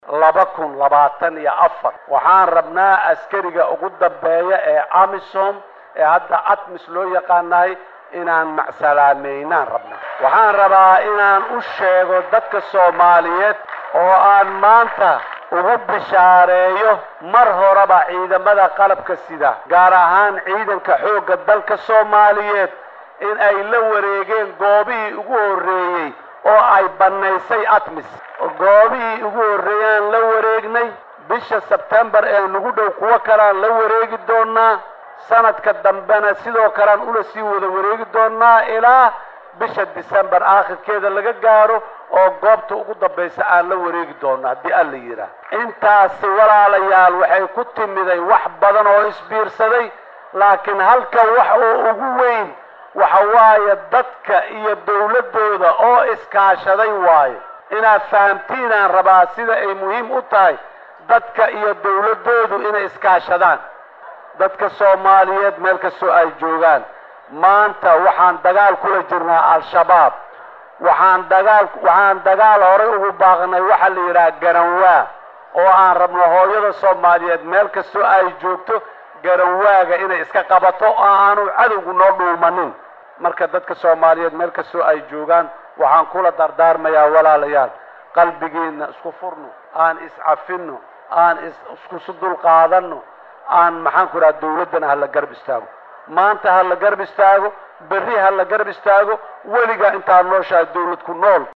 Madaxweynaha dalka Soomaaliya Xasan Sheekh Maxamuud oo khudbad ka jeedinayay munaasabada 1-da Luulyo ayaa ka hadlay saldhigyada ciidmada dowladda ay kala wareegeen howlgalka midowga Afrika ee ATMIS.